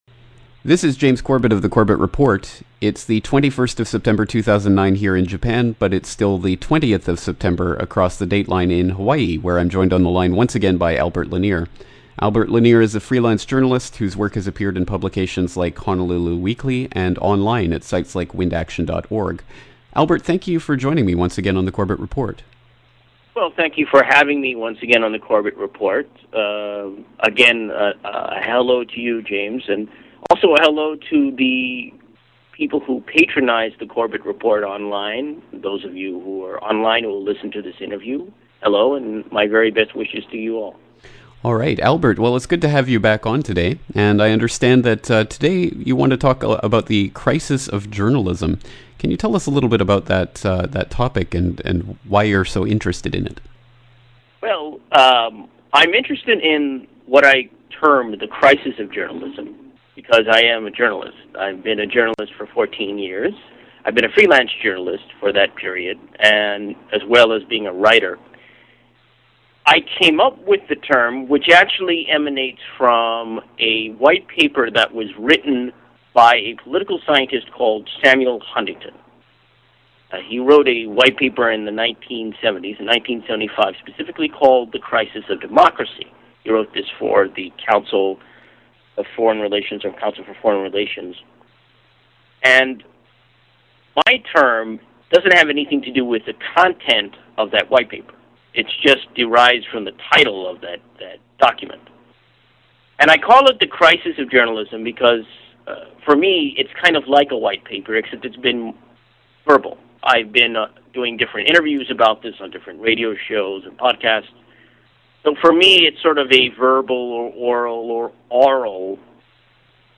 Interview 101